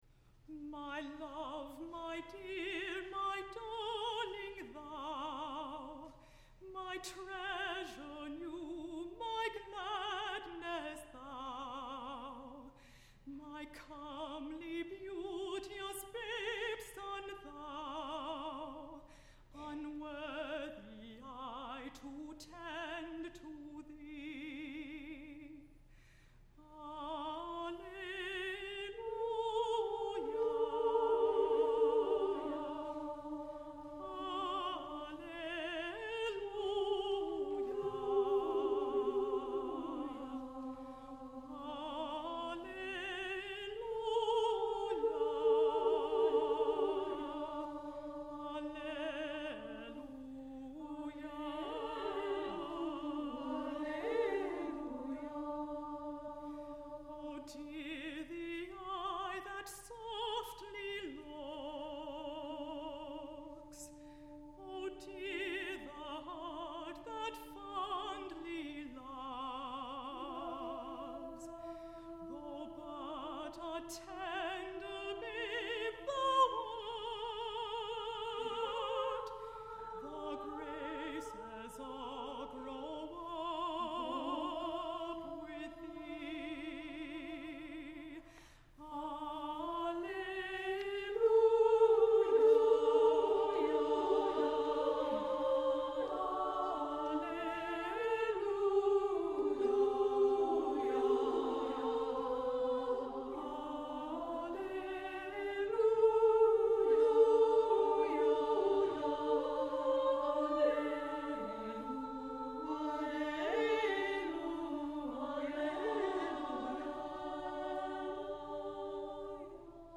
for SSA Chorus (1996)